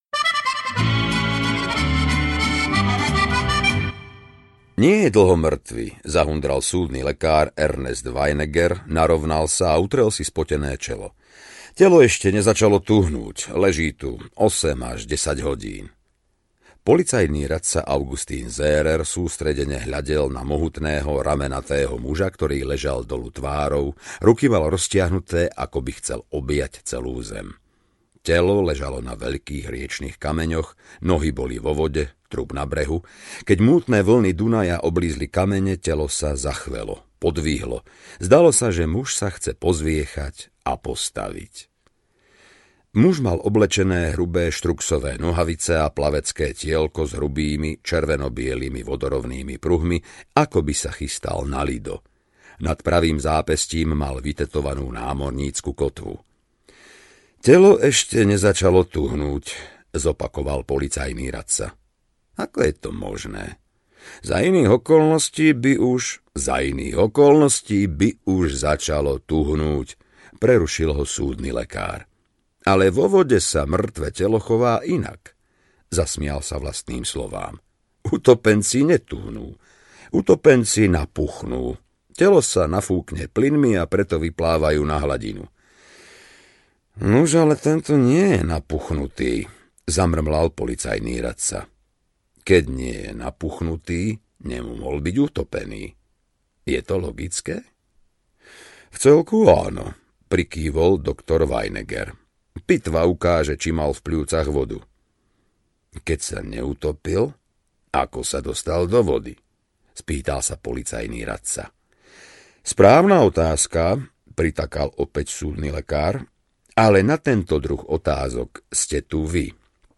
Hocikam mimo tohto sveta audiokniha
Ukázka z knihy